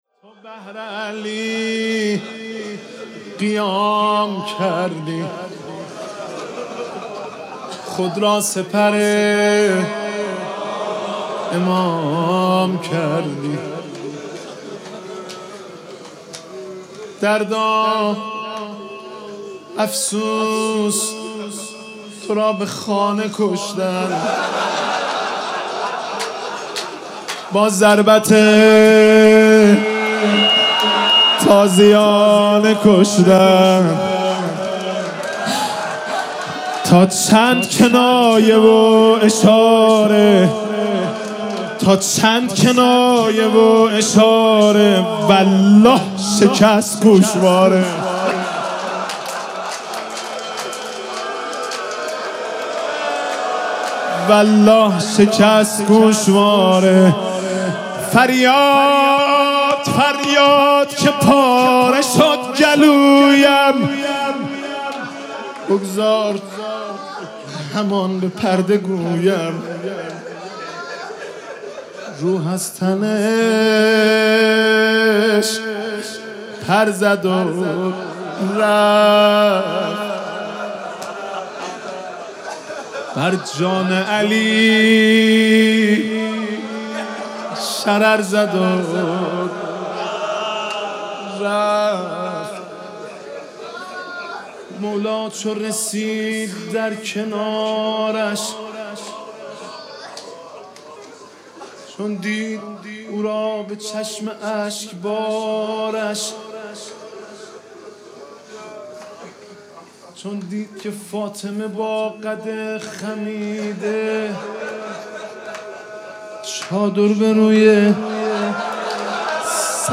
دانلود مداحی جدید از سید مهدی رسولی فاطمیه۱۴۰۰ – مجله نودیها